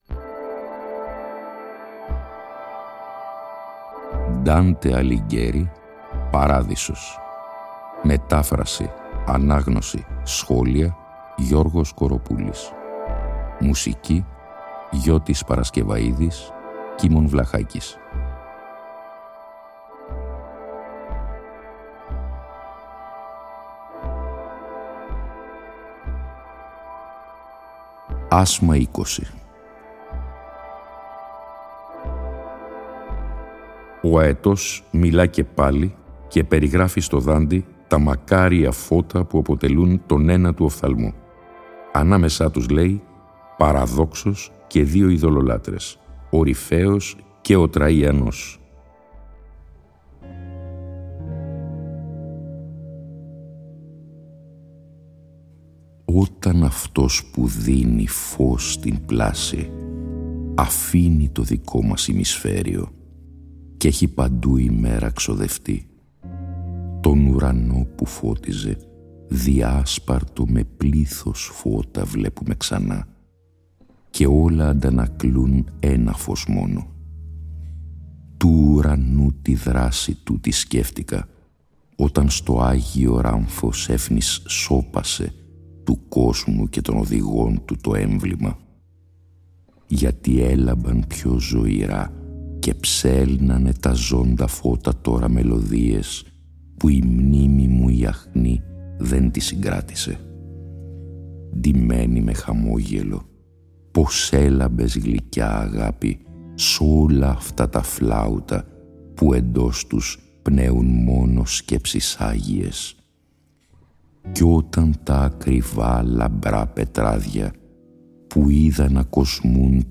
Η ανάγνωση των 33 ασμάτων του «Παραδείσου», σε 20 ημίωρα επεισόδια, (συνέχεια της ανάγνωσης του «Καθαρτηρίου», που είχε προηγηθεί) συνυφαίνεται και πάλι με μουσική